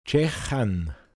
Pinzgauer Mundart Lexikon
Details zum Wort: dschechan. Mundart Begriff für arbeiten (schwer)